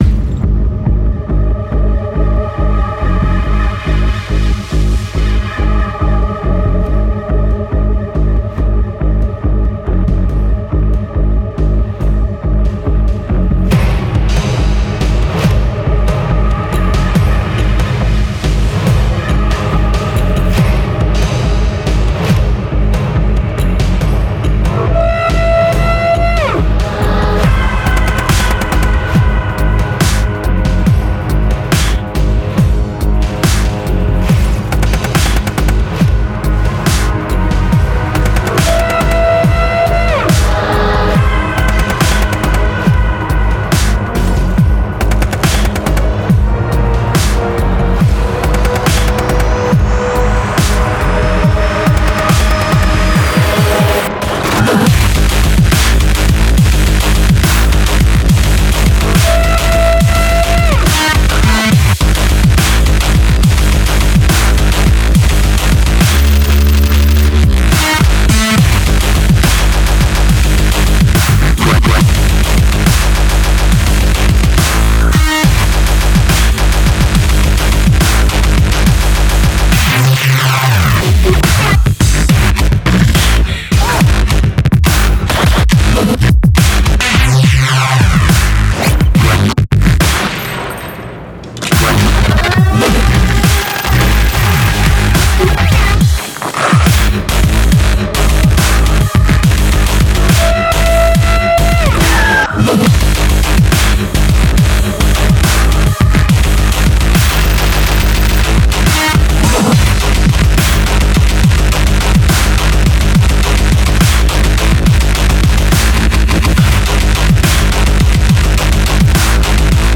Style: Dubstep, Drum & Bass